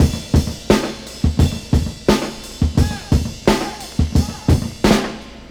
• 87 Bpm Drum Beat E Key.wav
Free drum beat - kick tuned to the E note. Loudest frequency: 1098Hz
87-bpm-drum-beat-e-key-dei.wav